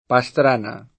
Pastrana [sp.